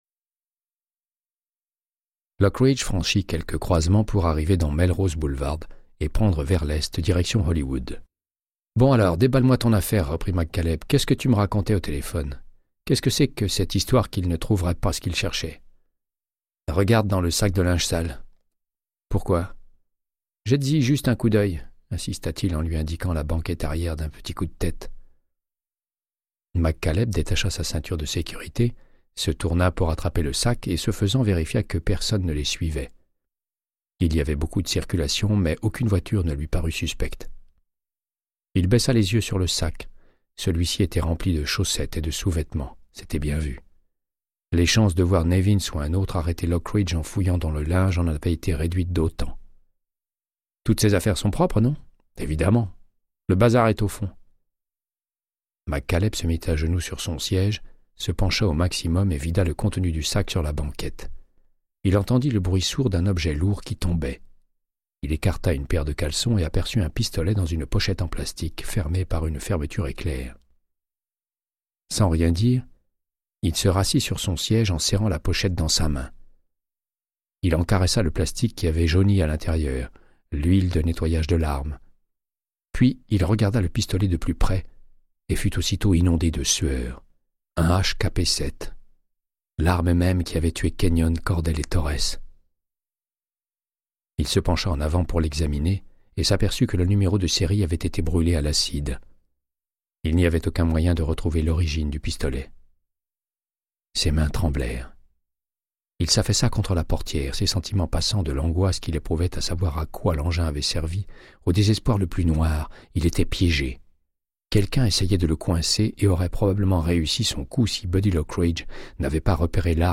Audiobook = Créance de sang, de Michael Connelly - 124